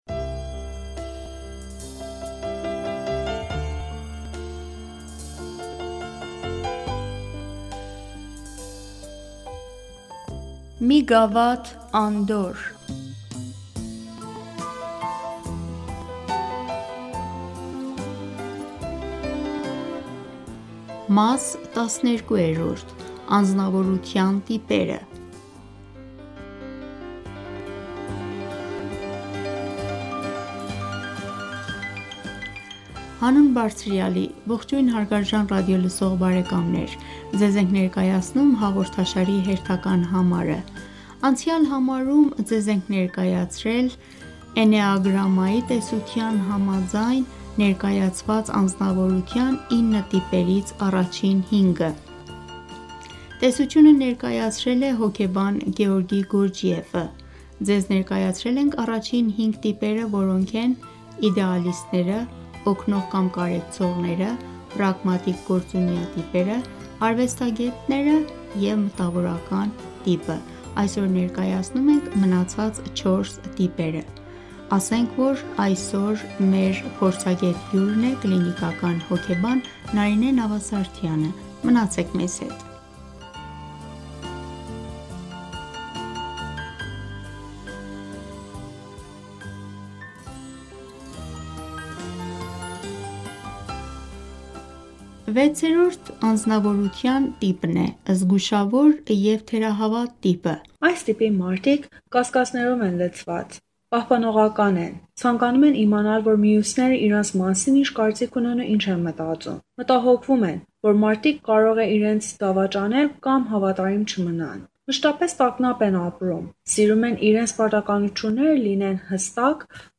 Հաղորդում